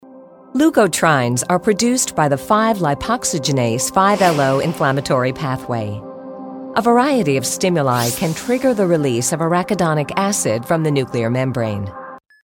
Info Medical